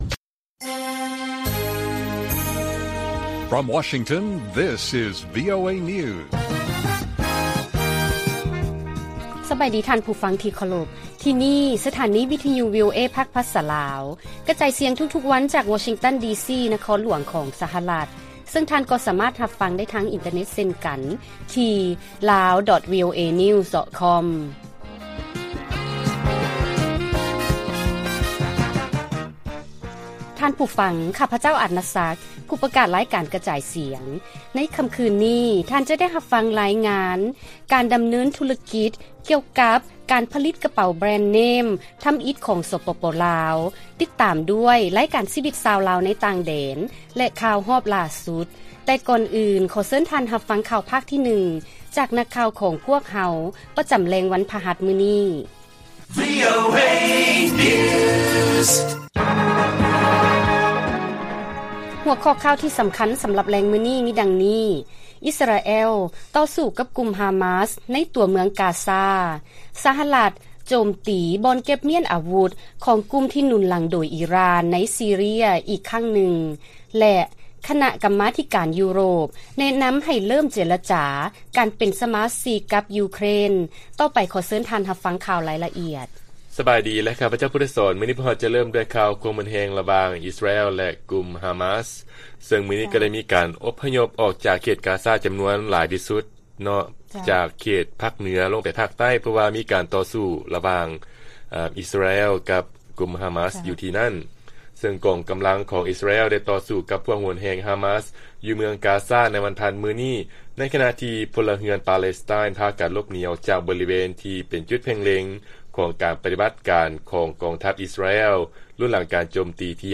ລາຍການກະຈາຍສຽງຂອງວີໂອເອລາວ: ອິສຣາແອລ ຕໍ່ສູ້ກັບກຸ່ມຮາມາສ ໃນຕົວເມືອງ ກາຊາ